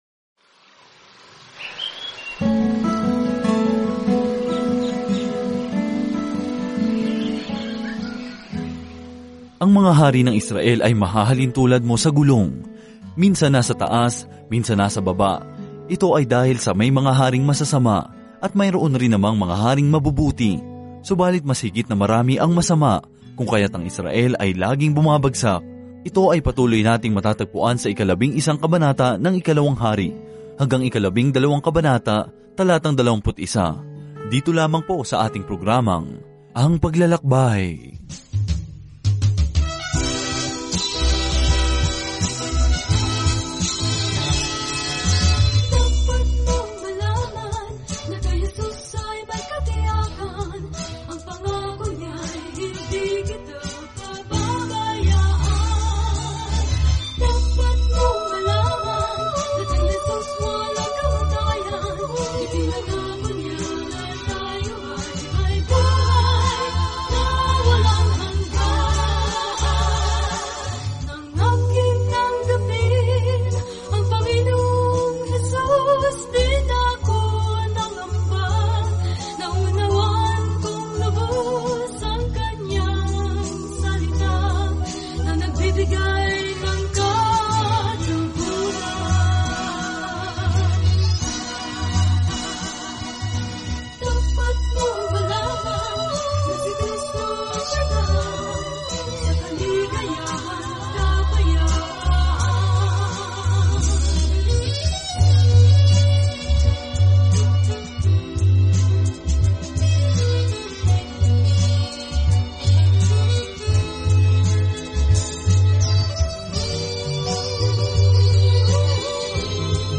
Banal na Kasulatan 2 Mga Hari 11 2 Mga Hari 12:1-21 Araw 6 Umpisahan ang Gabay na Ito Araw 8 Tungkol sa Gabay na ito Ang aklat ng Ikalawang Hari ay nagsasabi kung paano nawala sa paningin ng mga tao ang Diyos at kung paano niya sila hindi kailanman nakalimutan. Araw-araw na paglalakbay sa 2 Hari habang nakikinig ka sa audio study at nagbabasa ng mga piling talata mula sa salita ng Diyos.